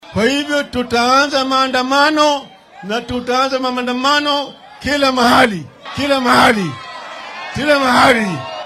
Madaxa isbeheysiga mucaaradka ee Azimio La Umoja-One Kenya Raila Odinga oo shalay isku soo bax siyaasadeed ku qabtay fagaaraha Kamukunji grounds ee ismaamulka Nairobi ayaa sheegay inay diyaar u yihiin inay wada hadal la yeeshaan dowladda balse ay jiraan shuruudo arrinkan la xiriira.